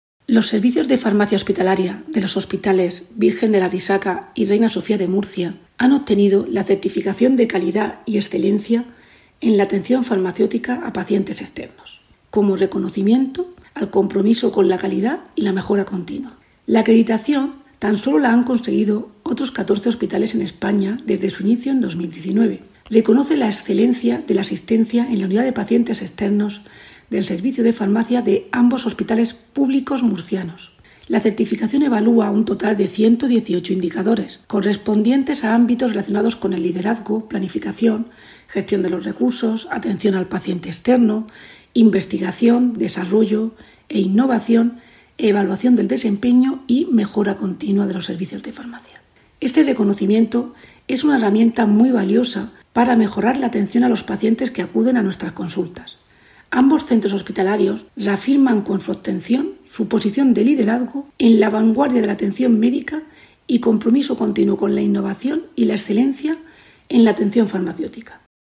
Declaraciones de la directora general de Asistencia Hospitalaria, Irene Marín, sobre la acreditación de Farmacia Hospitalaria que han recibido los hospitales Reina Sofía y Arrixaca [mp3]